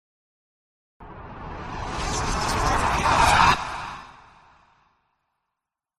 Ghostly Whisper
Ghostly Whisper is a free horror sound effect available for download in MP3 format.
048_ghostly_whisper.mp3